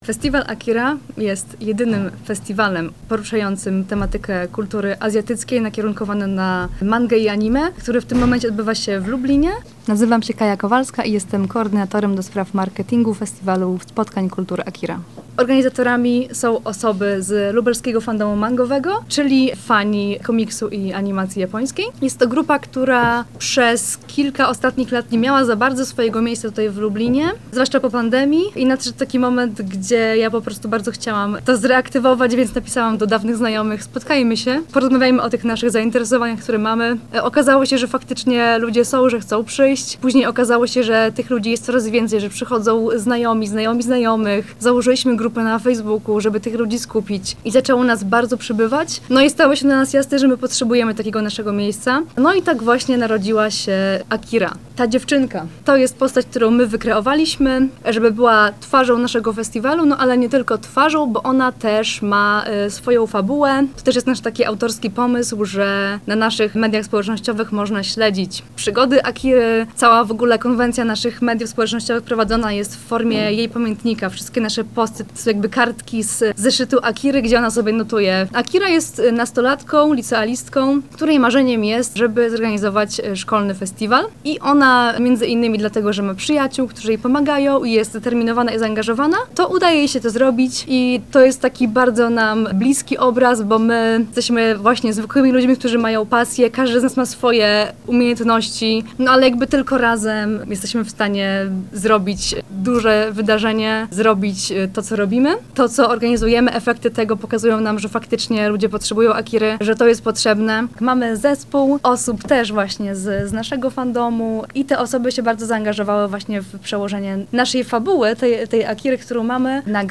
Więcej o Festiwalu opowiedzą jego organizatorzy.